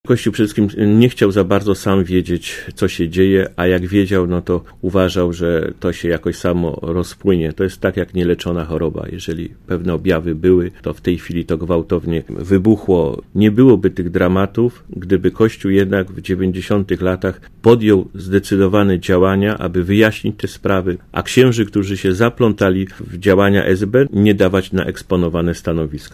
Grzechem zaniedbania nazywa brak decyzji Kościoła w sprawie lustracji ks. Tadeusz Isakowicz-Zaleski, legendarny przywódca nowohuckiej "Solidarności", Gość Radia ZET.
Mówi ks. Tadeusz Isakowicz-Zaleski